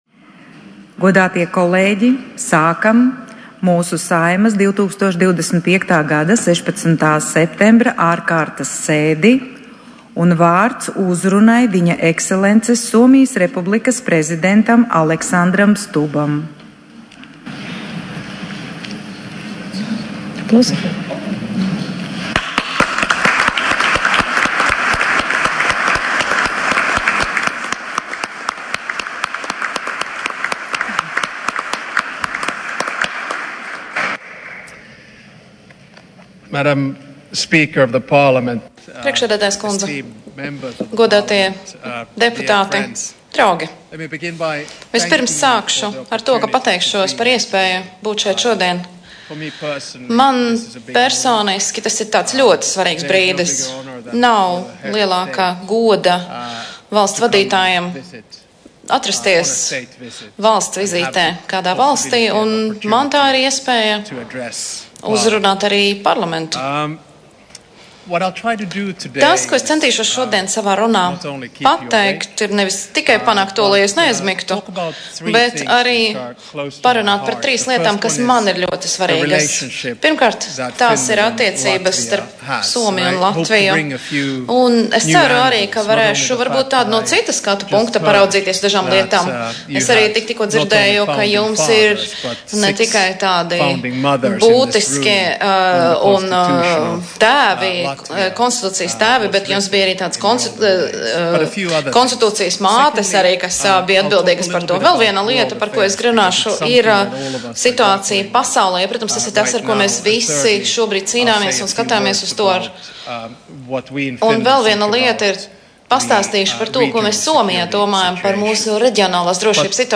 Saeimas sēdes: tiešraides un ieraksti.